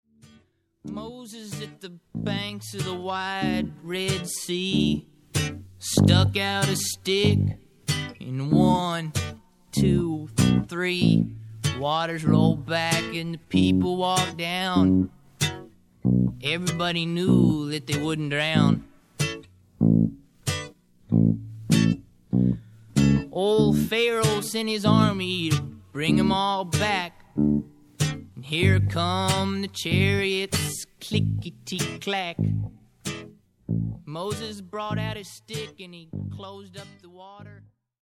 女性一人を含むテキサス出身の4人組